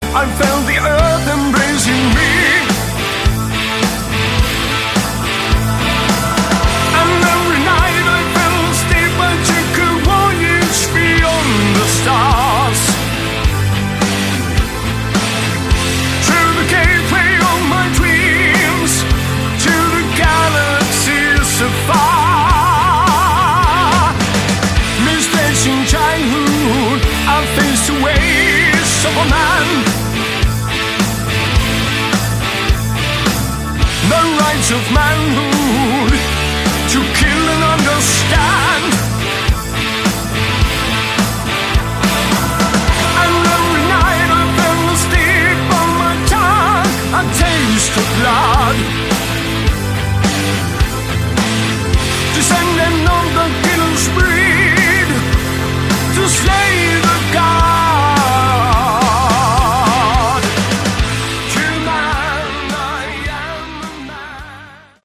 Category: Melodic Rock
vocals
lead guitars
bass, drums
keyboards